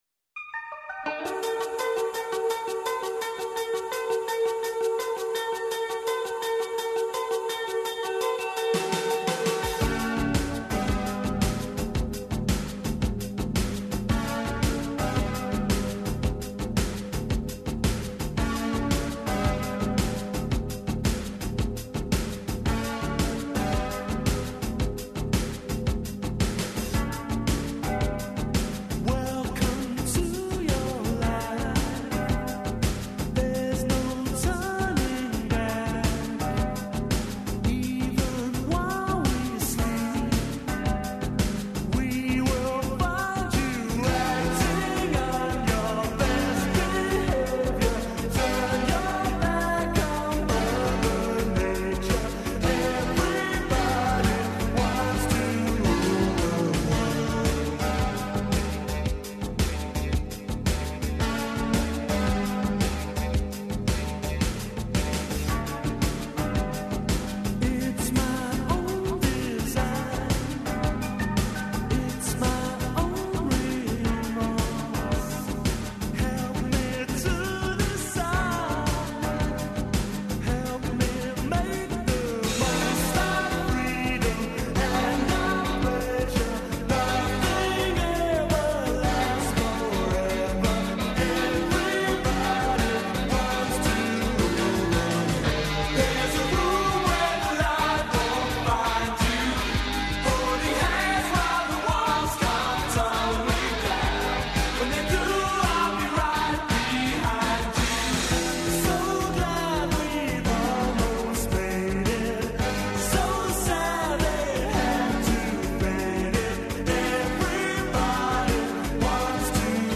Петак је и дан за рубрику ''Кад сам био студент" у којој ће овај пут говорити глумац Виктор Савић .